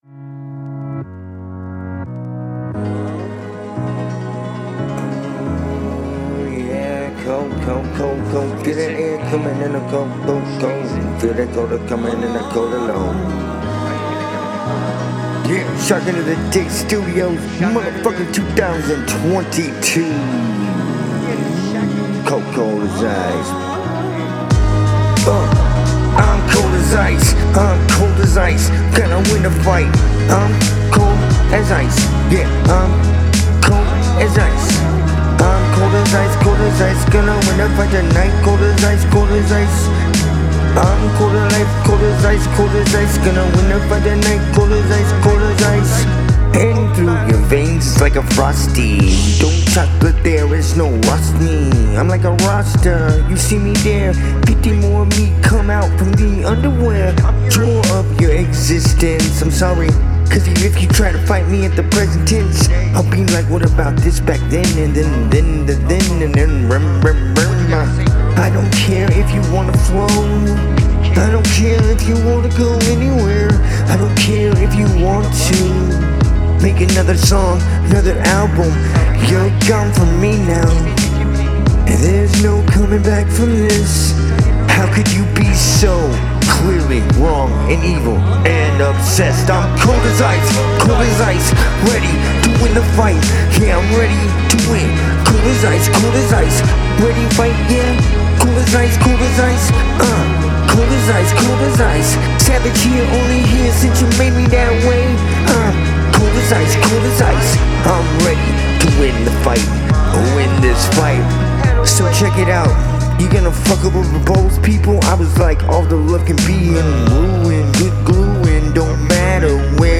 Date: 2026-04-07 · Mood: dark · Tempo: 91 BPM · Key: D major